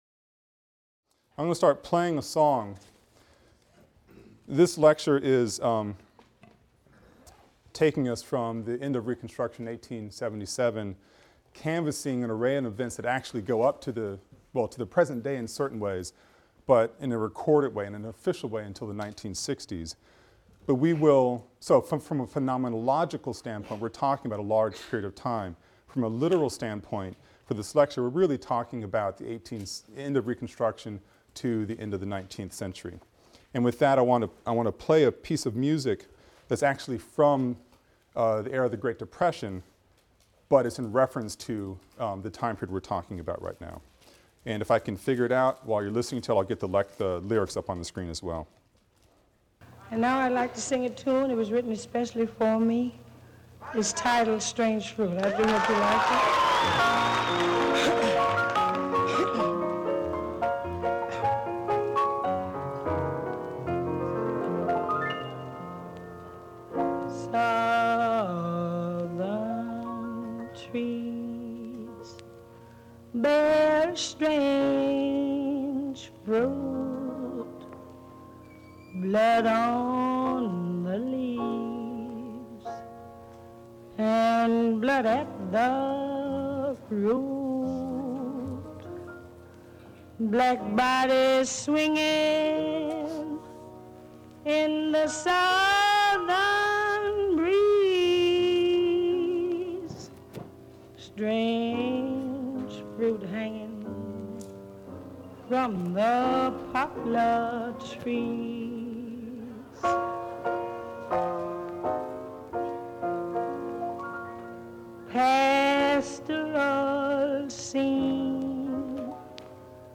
AFAM 162 - Lecture 4 - Reconstruction (continued) | Open Yale Courses